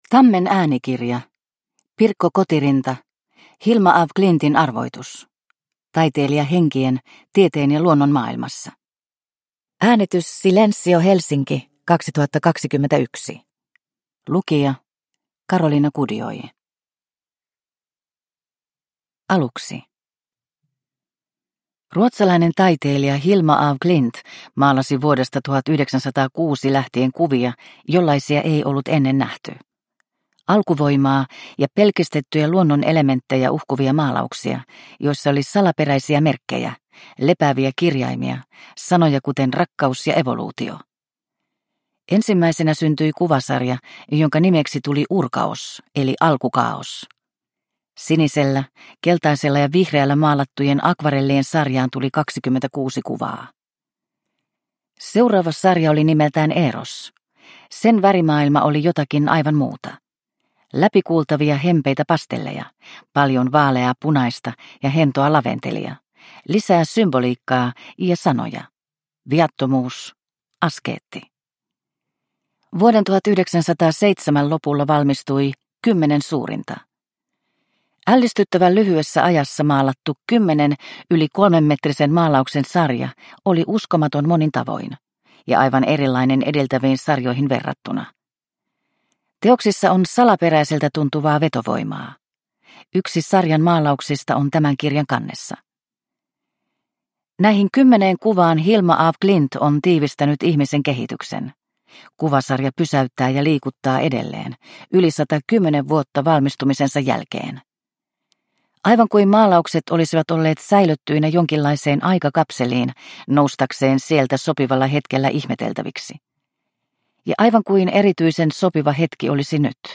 Hilma af Klintin arvoitus – Ljudbok – Laddas ner